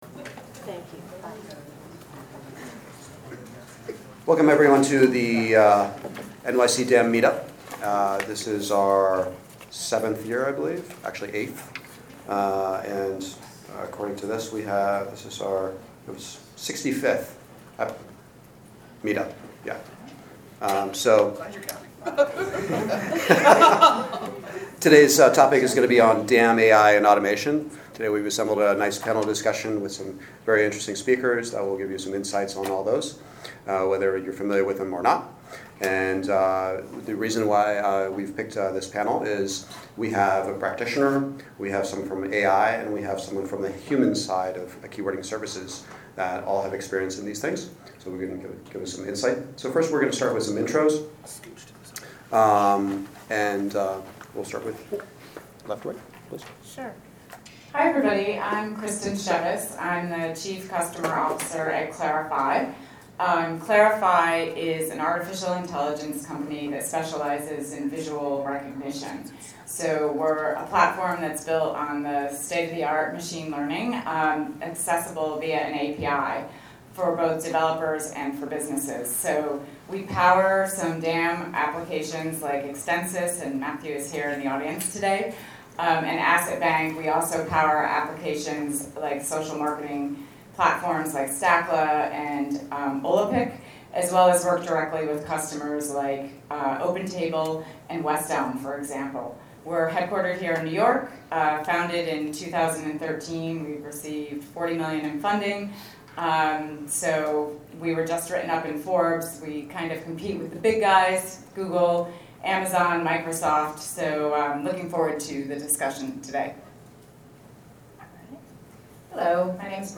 In case you missed this NYC DAM Meetup in person or want to review this passionate discussion again, below is the unedited audio recording of this panel discussion (Duration: 74 minutes)